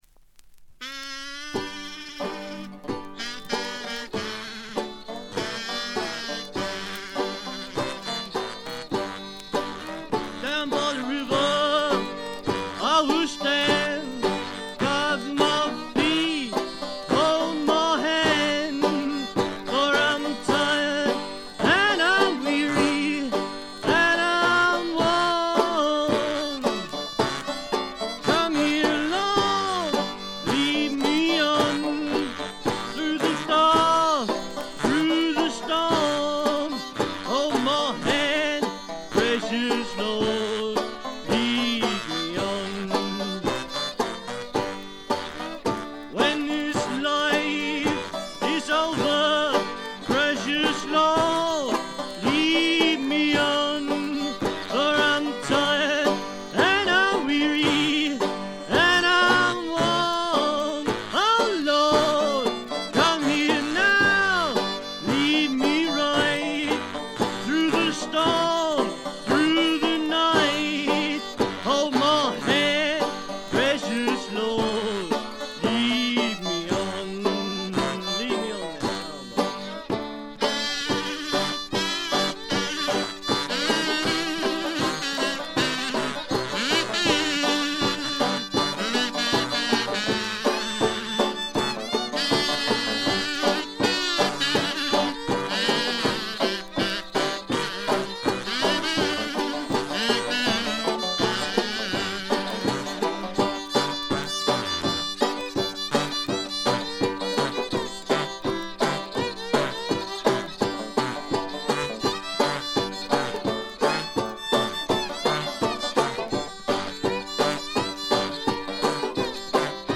軽微なバックグラウンドノイズのみ。
試聴曲は現品からの取り込み音源です。
lead vocals, kazoo, guitar, banjo, bass drum
washboard, harmonica, jug, hoot